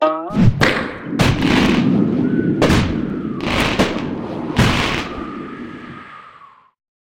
slingshot.ogg.mp3